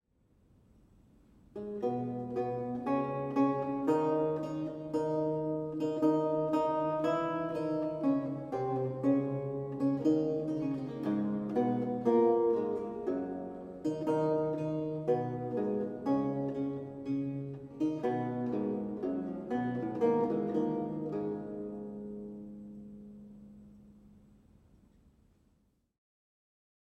Audio recording of a lute piece from the E-LAUTE project